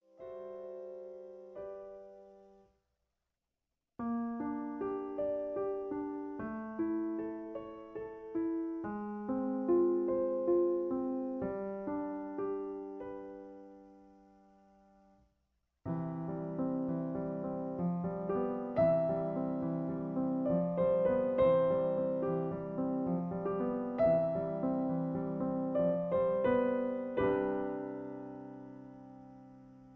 Original key (C). Piano Accompaniment